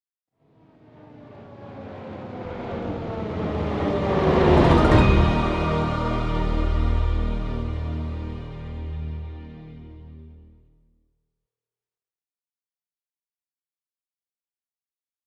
begin-game epic-swell level-up logo-jingle new-game new-quest quest-accept quest-complete sound effect free sound royalty free Gaming